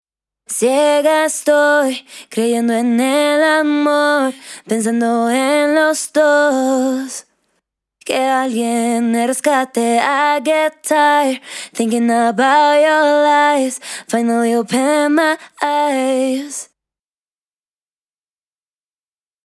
ロボットボイスの元となる女性ボーカル（アカペラ）の音声
Vocal_dry.mp3